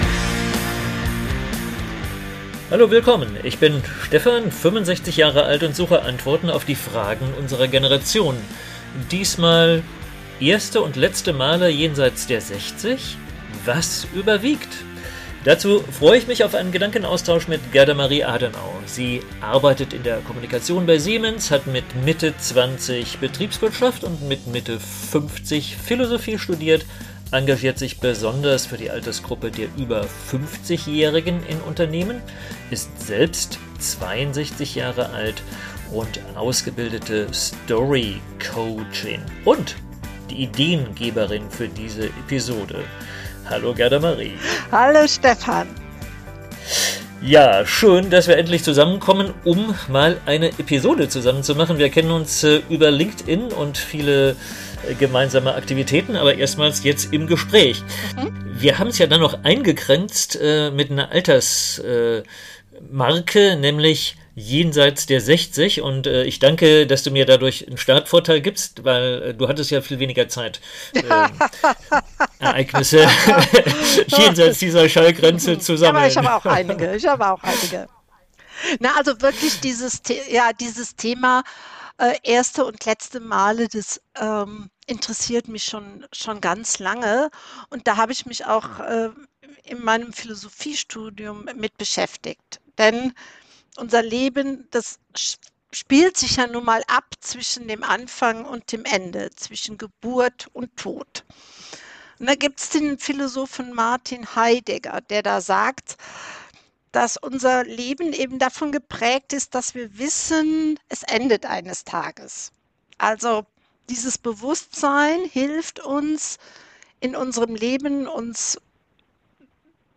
Ein überraschender Gedankenaustausch.